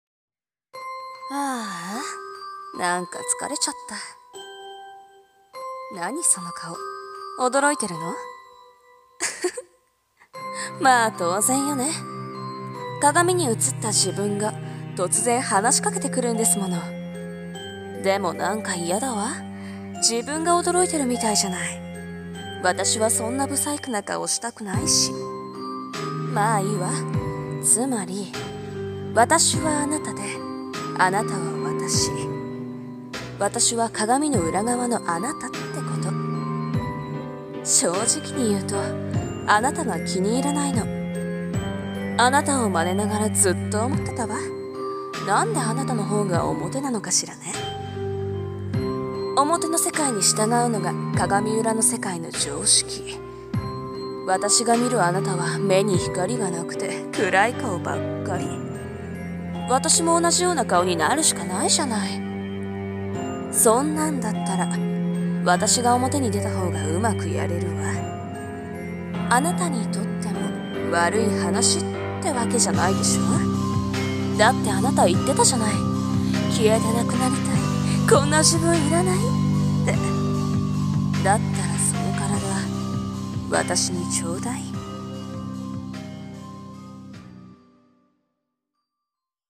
声劇 裏側の世界 (女性ver.)